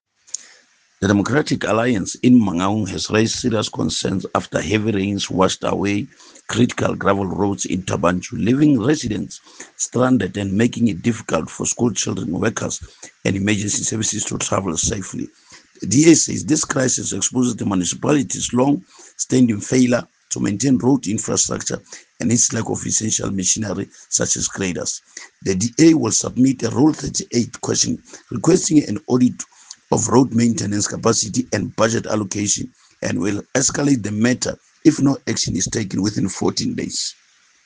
Sesotho soundbites by Cllr Tumelo Rammile and